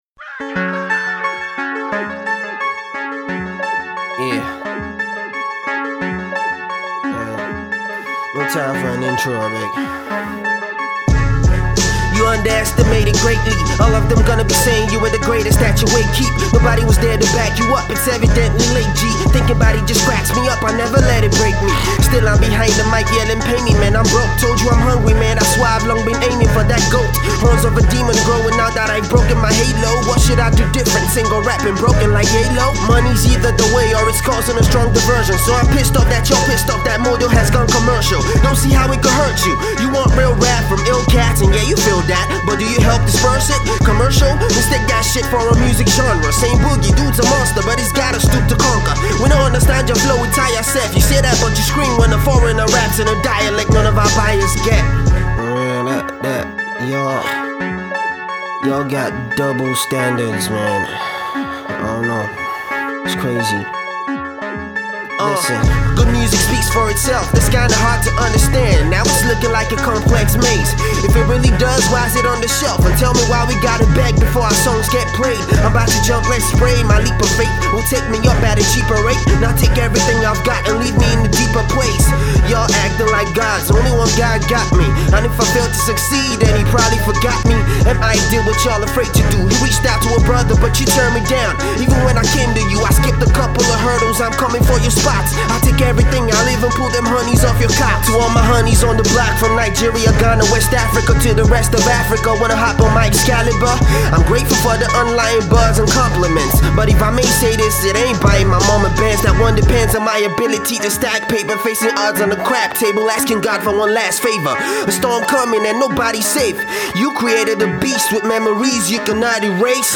in his usual smooth and rapid flow